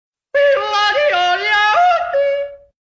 Sound Effects
Goofy Ahh Yodeling Sound